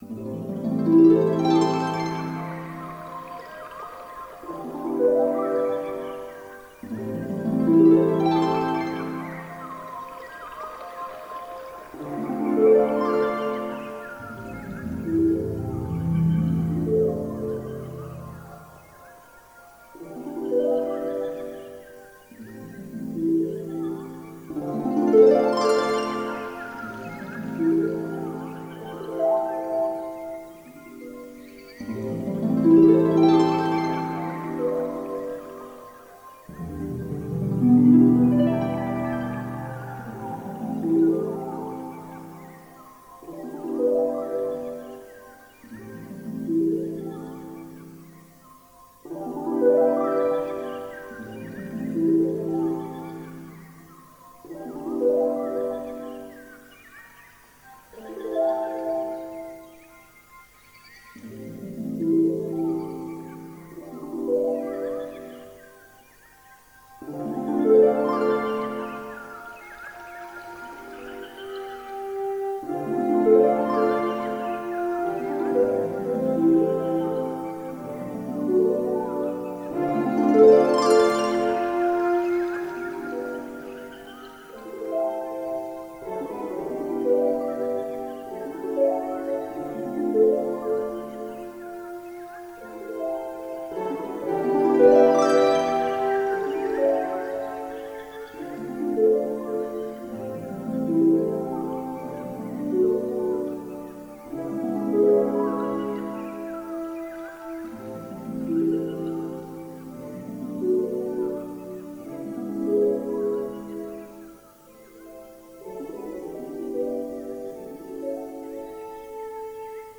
Gemafreie Musik - Royalty Free Music